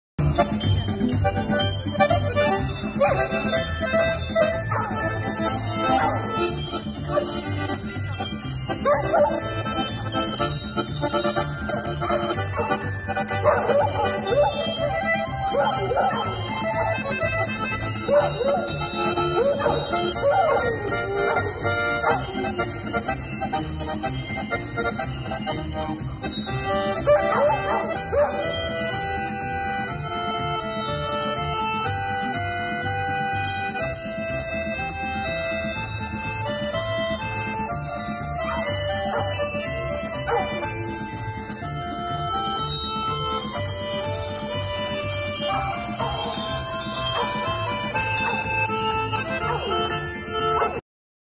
Кисловодск. Курортный бульвар. Уличные музыканты, как воздух апреля 2013.
muzyikantyi-2.mp3